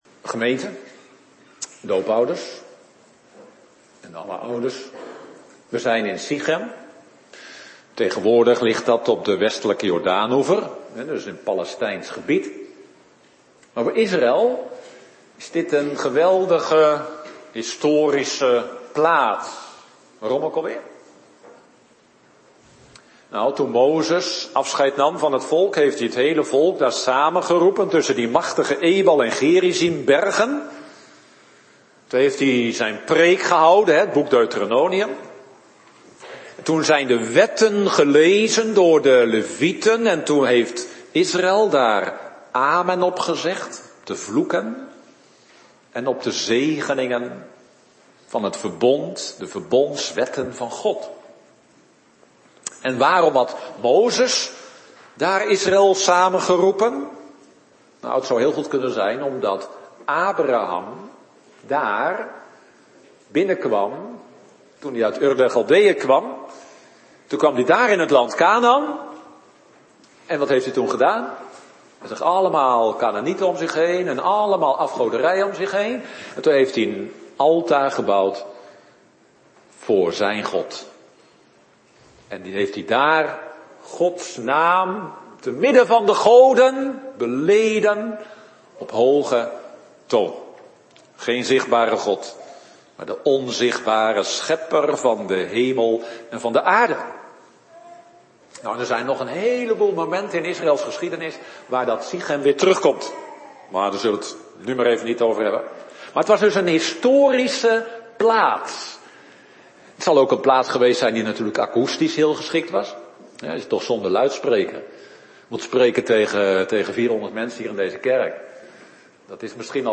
Soort Dienst: Bediening Heilige Doop